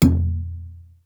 Exclamation.wav